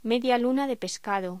Locución: Media luna de pescado
voz